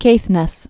(kāthnĕs, kāth-nĕs)